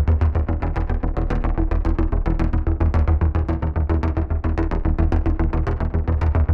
Index of /musicradar/dystopian-drone-samples/Droney Arps/110bpm
DD_DroneyArp3_110-E.wav